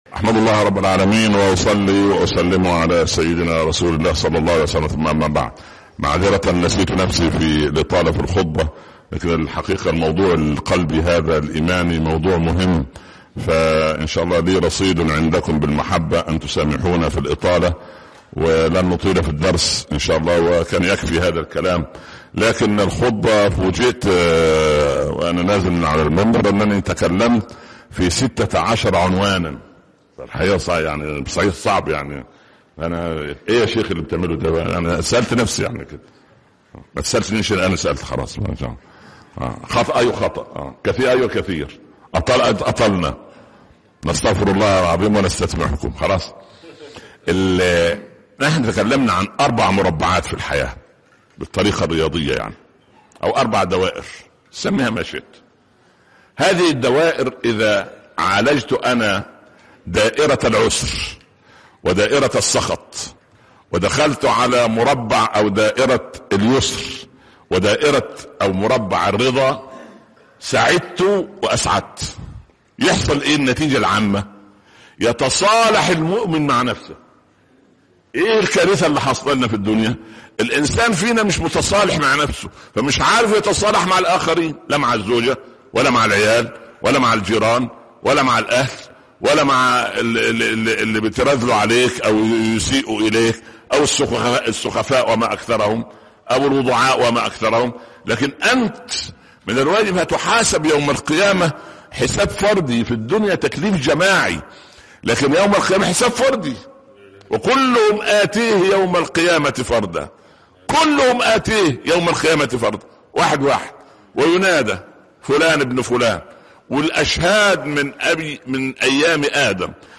من السخط إلى الرضا (26/1/2018) درس الجمعة - الشيخ عمر بن عبدالكافي